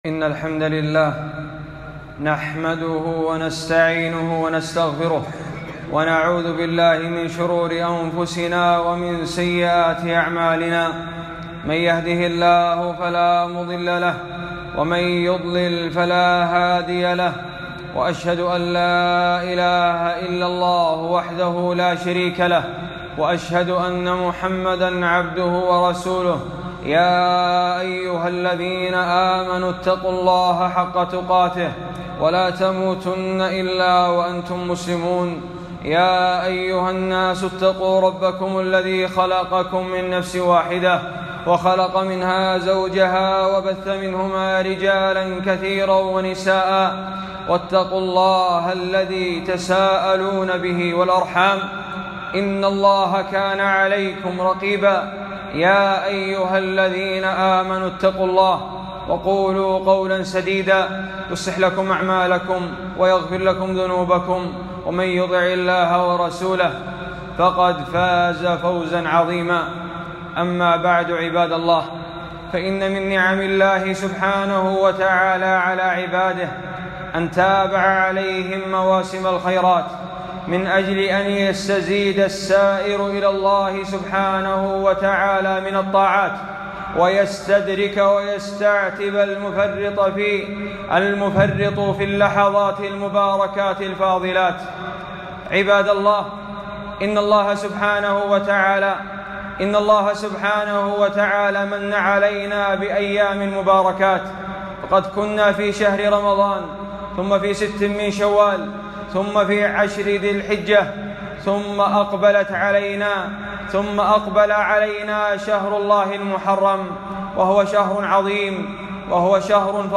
خطبة - التذكير بفضل شهر الله المحرم والتحذير من بدع آخر العام